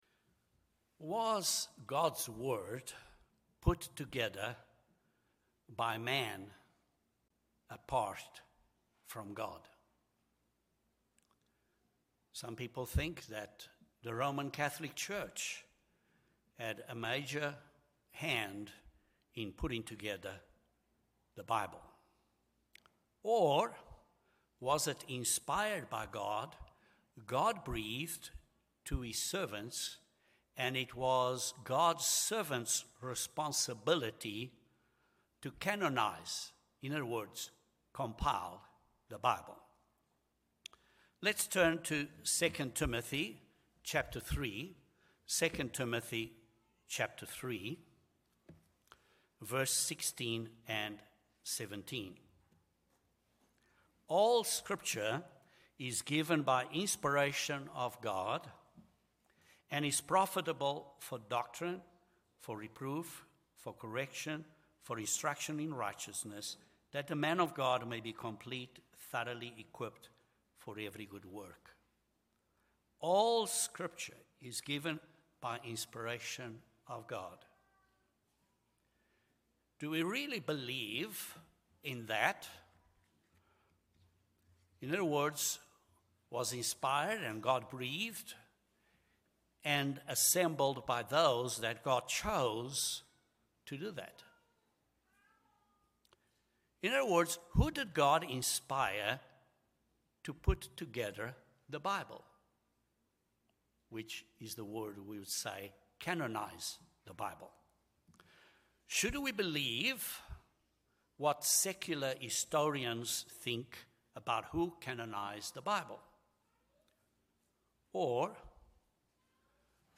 The Bible is complete and there are no missing books. This sermon series describes how God inspired the writing and compilation of His Word. In this first sermon of this series the canonization of the Old Testament is covered.